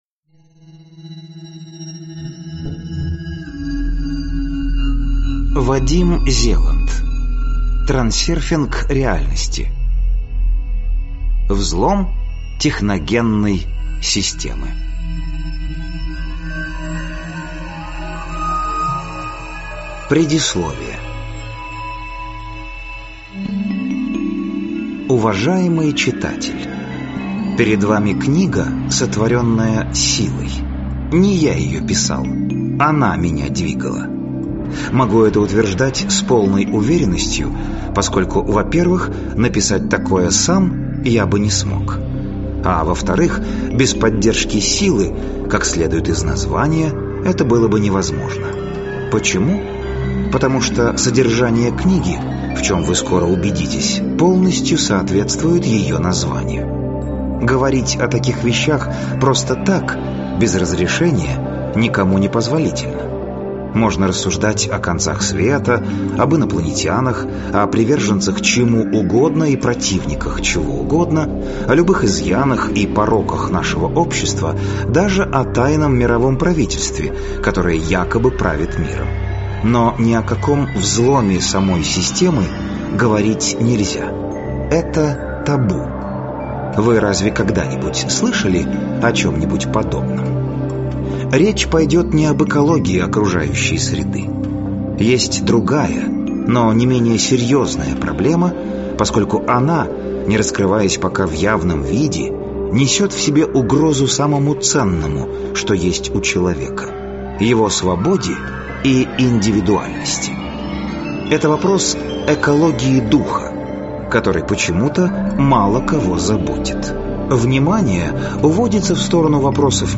Аудиокнига Взлом техногенной системы | Библиотека аудиокниг